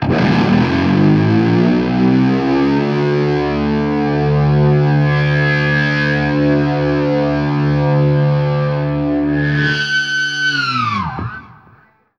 DIVEBOMB 1-L.wav